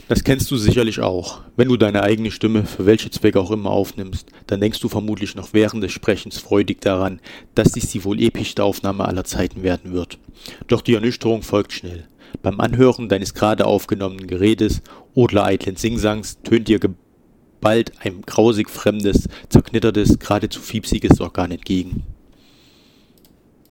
Hallo allerseits, ich möchte an meiner Stimme "arbeiten", da sie meiner Meinung nach recht dünn und auch etwas heißer klingt. Ich würde euch gerne einmal um eine Einschätzung meiner Sprechstimme bitten. Möglicherweise lieg ich ja total daneben, aber ich finde es klingt doch bescheiden.